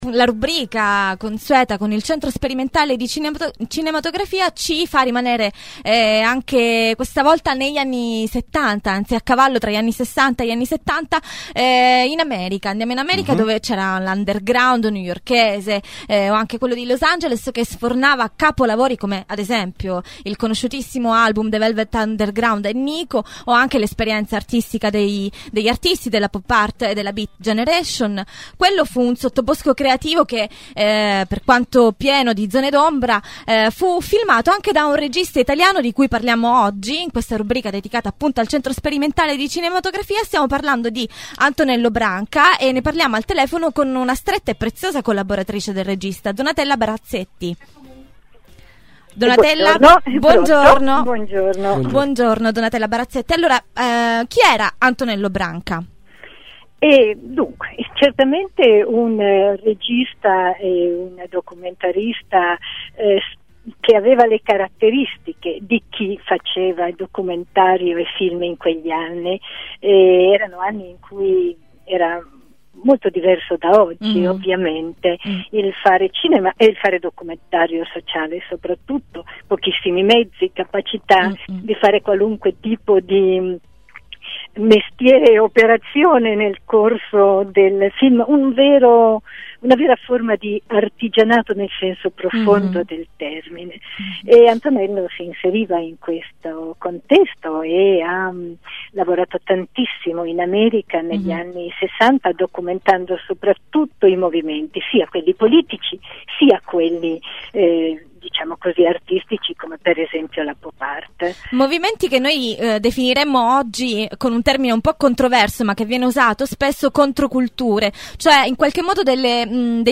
Serivizio su Seize the Time